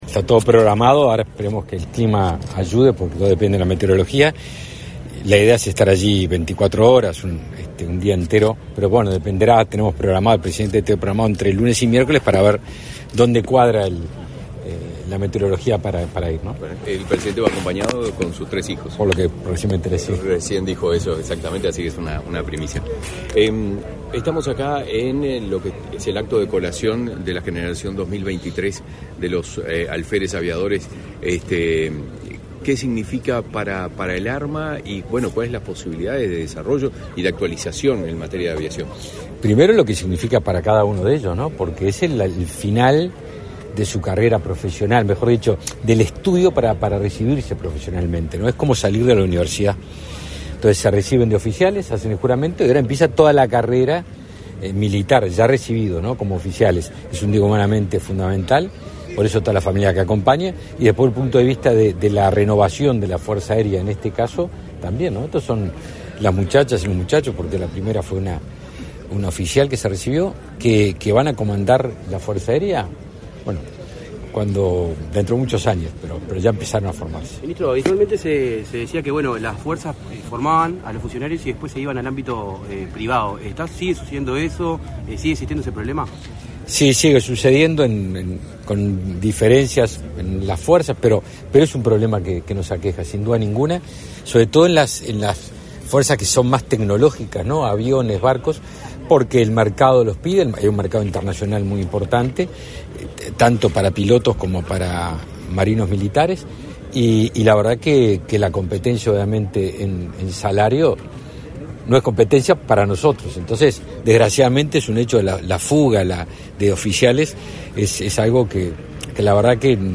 Declaraciones a la prensa del ministro de Defensa Nacional, Javier García
Declaraciones a la prensa del ministro de Defensa Nacional, Javier García 12/12/2023 Compartir Facebook X Copiar enlace WhatsApp LinkedIn Con la participación del presidente de la República, Luis Lacalle Pou, se realizó, este 12 de diciembre, la ceremonia de clausura de cursos y colación de grados 2023. Tras el evento, el ministro de Defensa Nacional, Javier García, realizó declaraciones a la prensa.